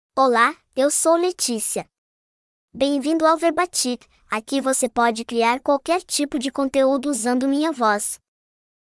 FemalePortuguese (Brazil)
LeticiaFemale Portuguese AI voice
Leticia is a female AI voice for Portuguese (Brazil).
Voice sample
Female
Leticia delivers clear pronunciation with authentic Brazil Portuguese intonation, making your content sound professionally produced.